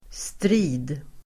Uttal: [stri:d]